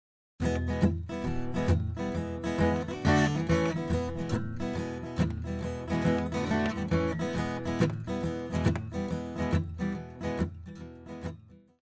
For my original version I was playing an acoustic guitar for rhythm back up.
It appears to be using all downstrokes on the guitar, where I think I’m using down up down up-muted .